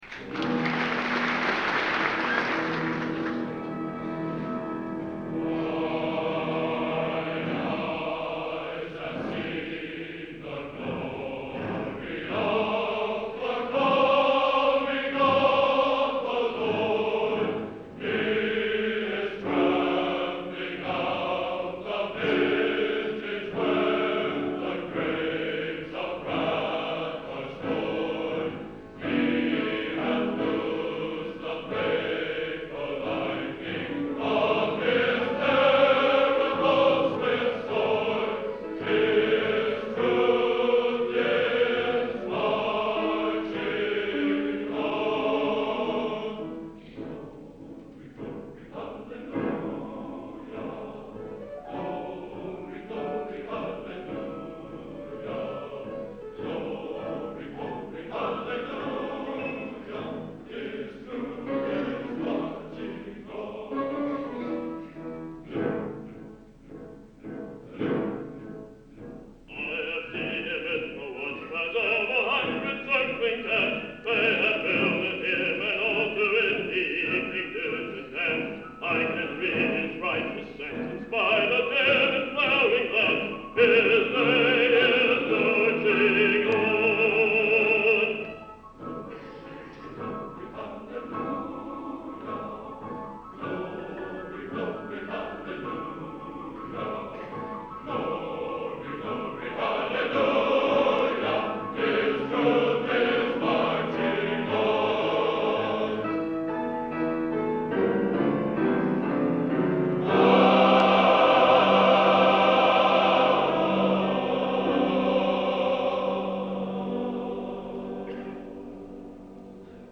Genre: Patriotic | Type: